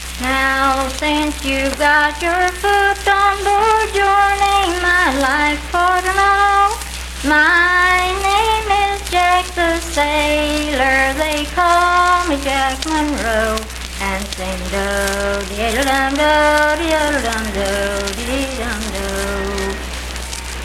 Unaccompanied vocal music
Voice (sung)
Spencer (W. Va.), Roane County (W. Va.)